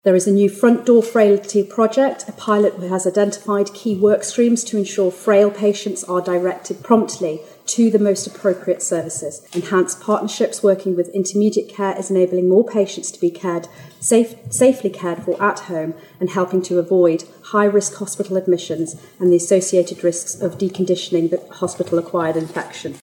Minister Christian says protecting those who are frail will be among the priorities: